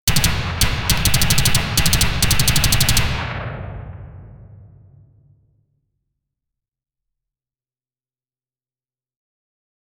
giant gun mechanism, sci fi.
giant-gun-mechanism-sci-f-3n47oksz.wav